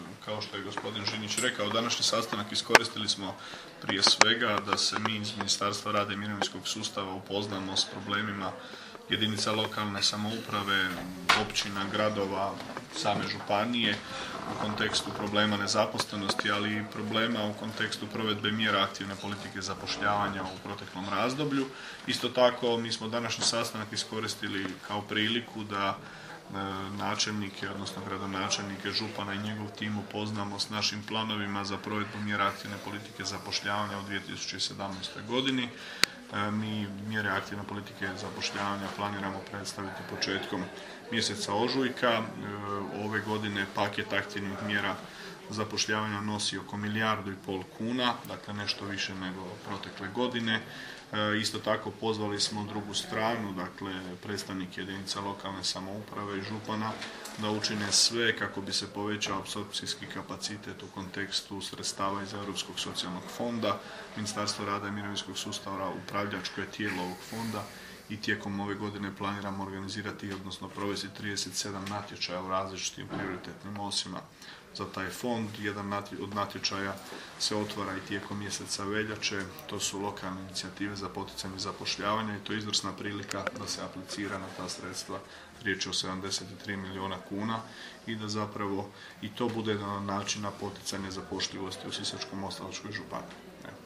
Ministar rada i mirovinskog sustava Tomislav Ćorić: